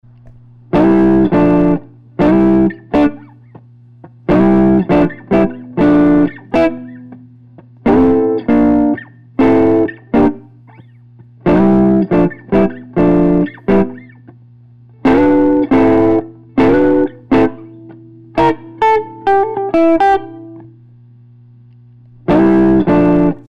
The JJ-150 is in stock and many folks around these parts (San Francisco bay area) prefer it for the same EH-150 breakup effect
I think they sound very similar.